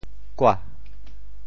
寡 部首拼音 部首 宀 总笔划 14 部外笔划 11 普通话 guǎ 潮州发音 潮州 gua2 文 中文解释 寡〈形〉 少;缺少 [few] 寡,少也。